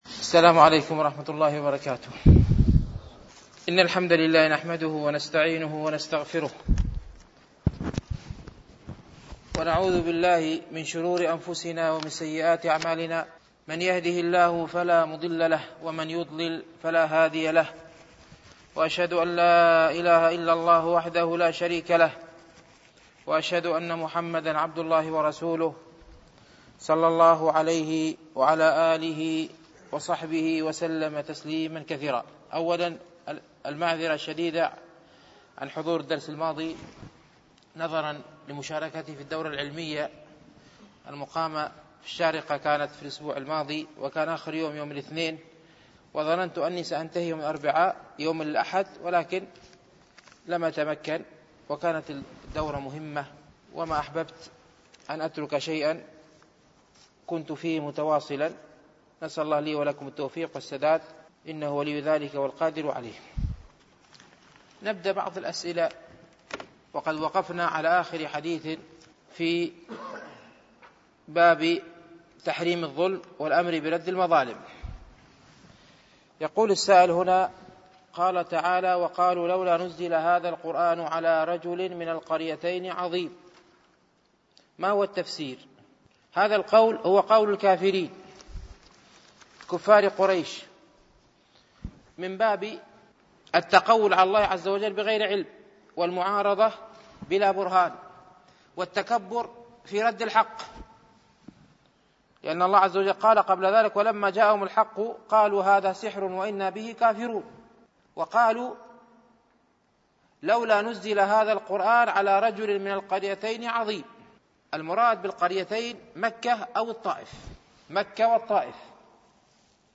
شرح رياض الصالحين ـ الدرس السابع والخمسون